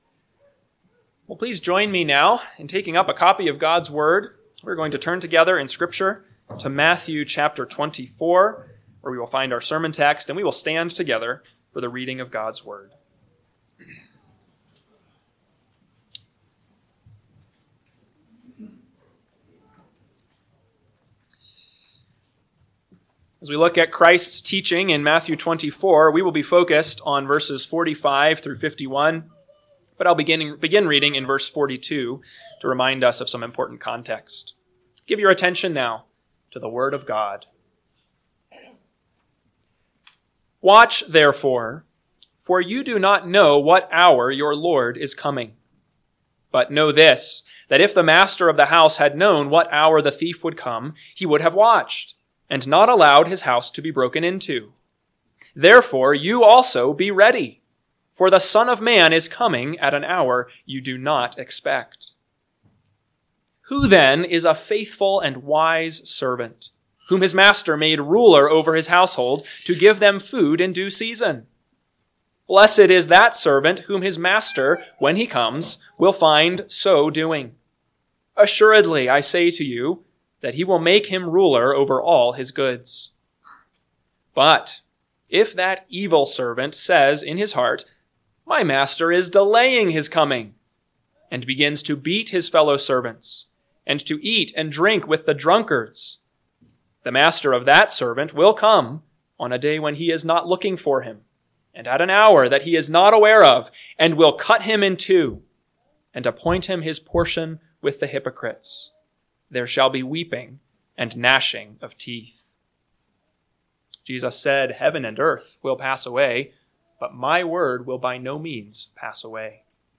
AM Sermon – 1/12/2025 – Matthew 24:45-51 – Northwoods Sermons